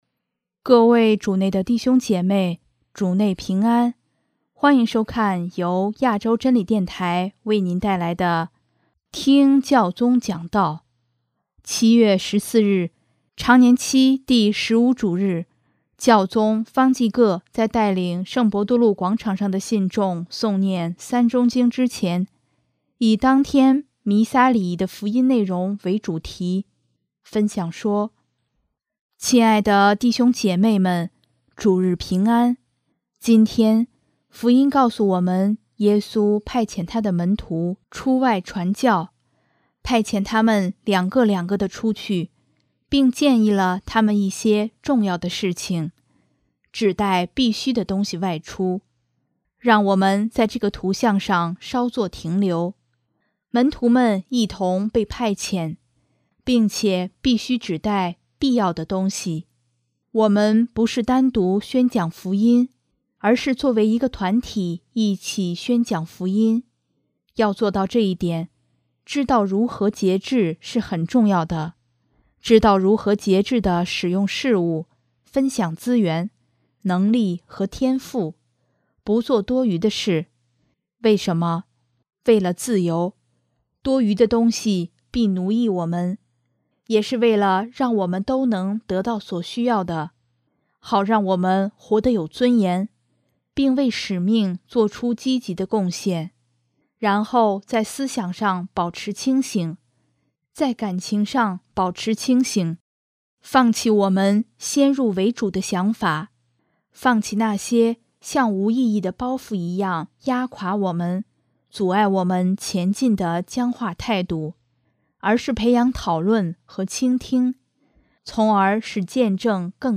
7月14日，常年期第十五主日，教宗方济各在带领圣伯多禄广场上的信众诵念《三钟经》之前，以当天弥撒礼仪的福音内容为主题，分享说：